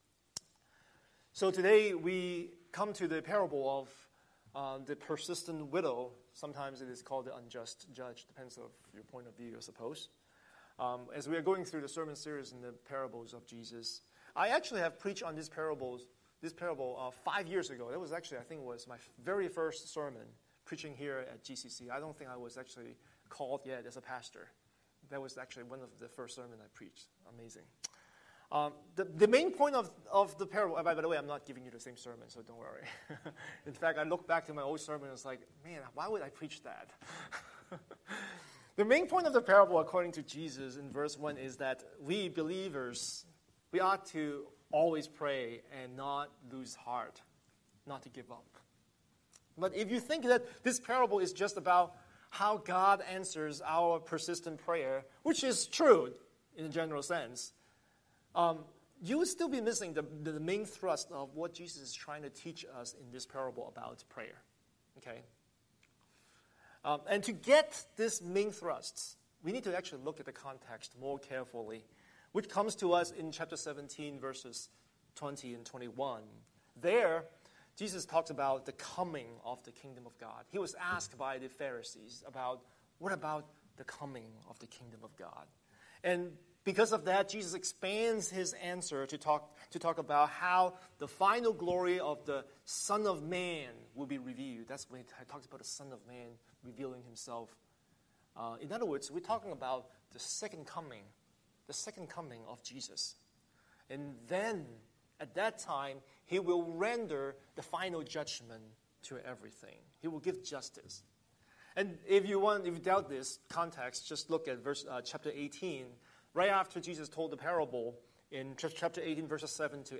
Scripture: Luke 18:1–8 Series: Sunday Sermon